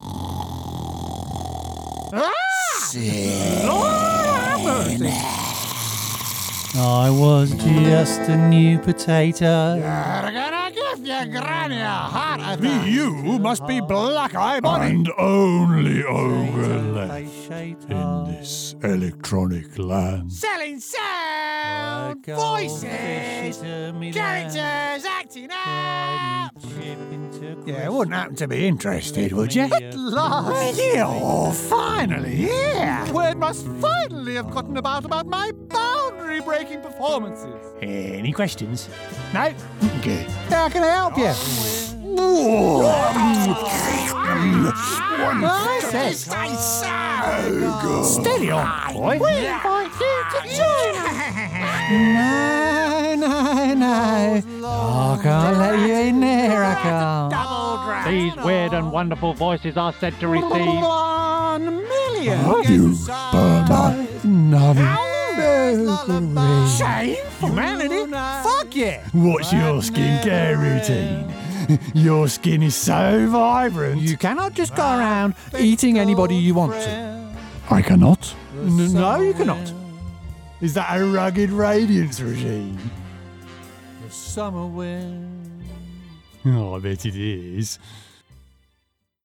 Character, Cartoon and Animation Voice Overs
Adult (30-50) | Older Sound (50+)